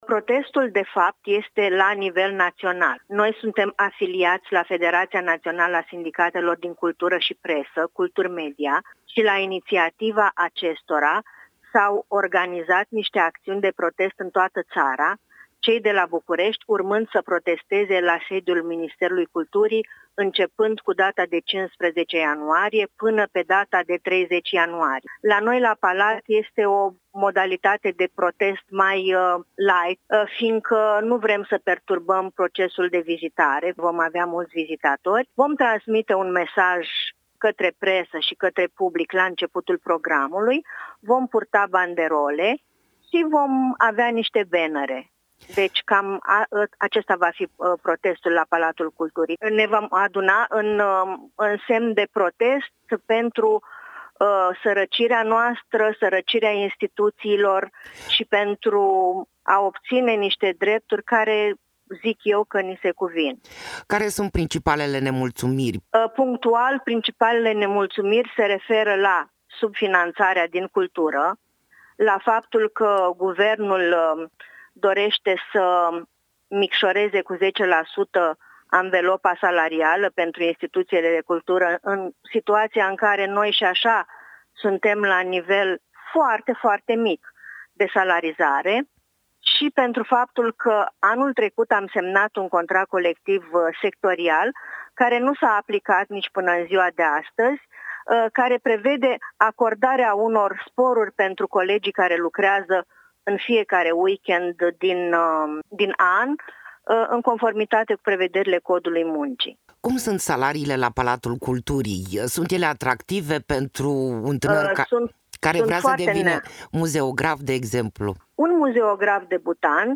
Interviu-protest-palat.mp3